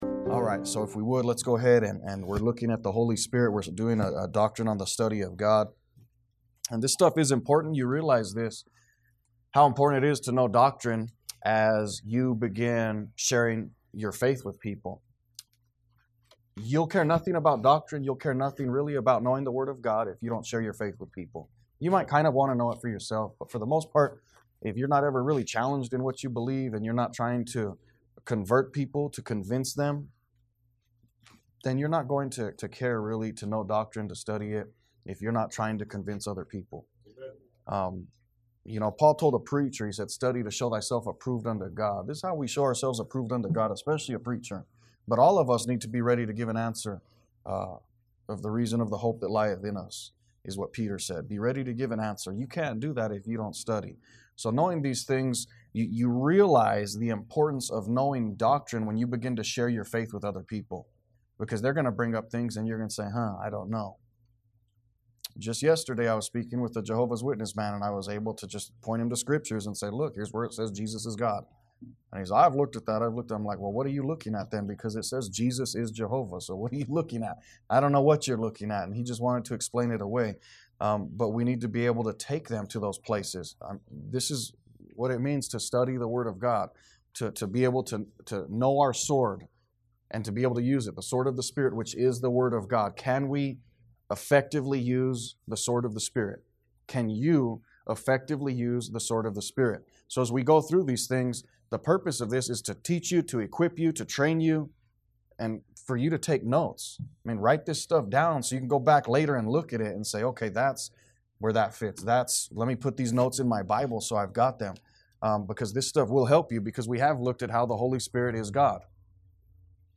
A message from the series "The Book of Acts."